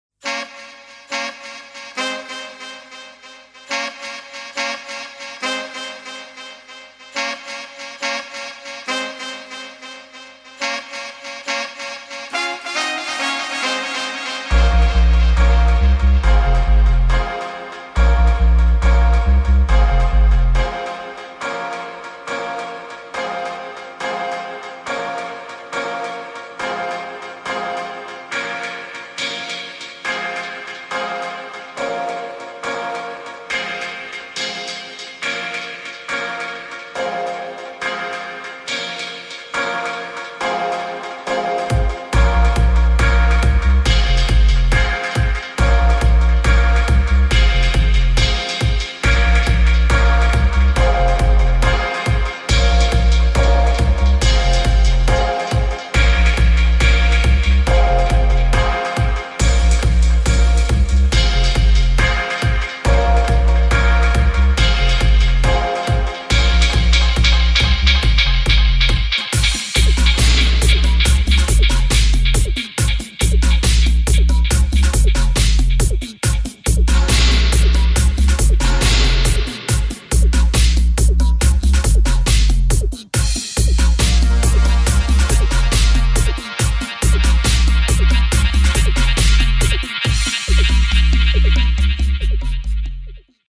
[ REGGAE | DUB ]
Dub Version